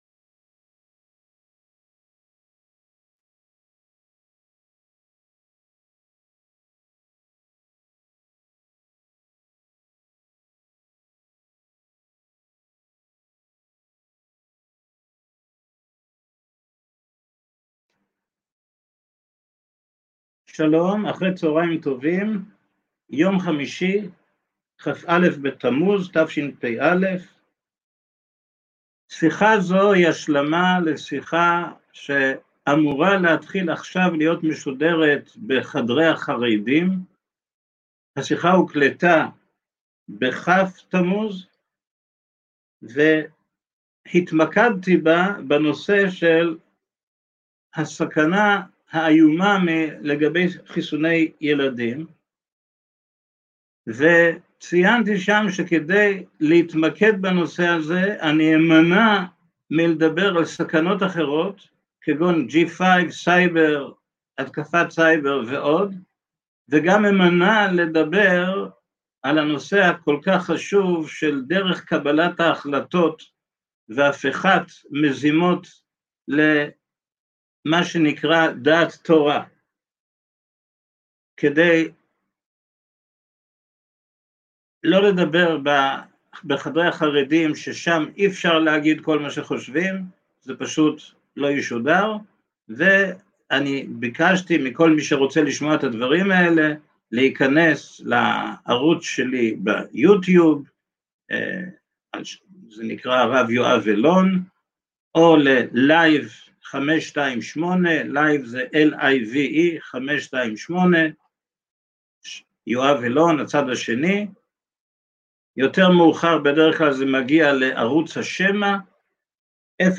בשידור חי השלמת שיחה שצונזרה מחדרי חרדים